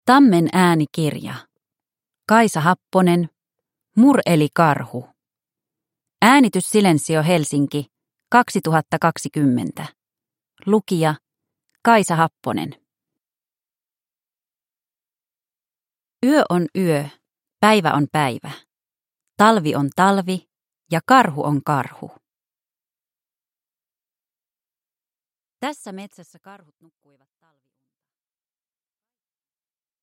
Mur, eli karhu – Ljudbok – Laddas ner